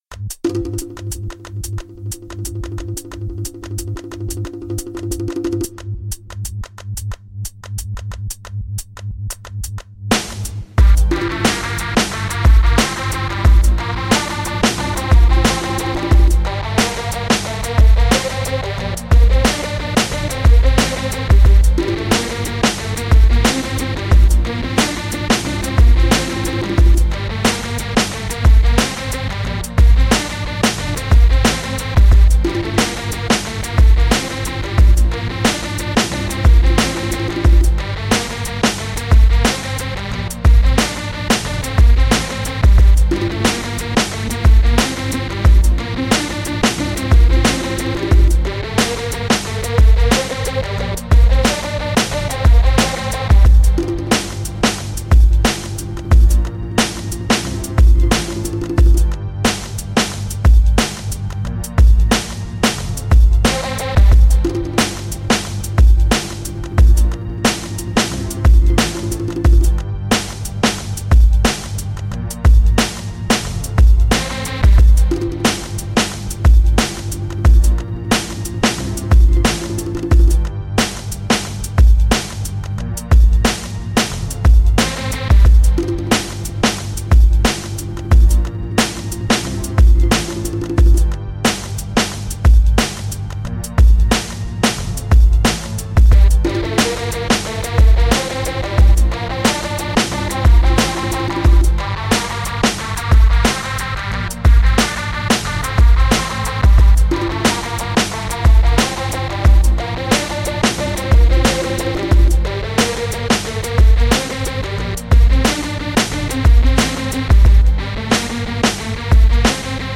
It’s a basic hip hop beat